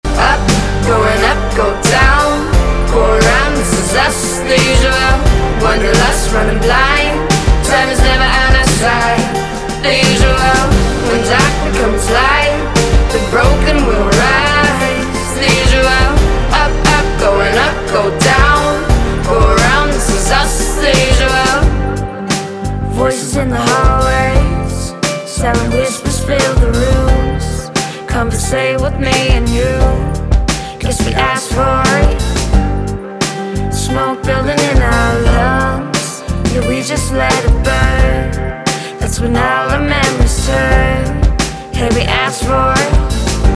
• Качество: 320, Stereo
поп
саундтреки
дуэт
красивый женский голос
alternative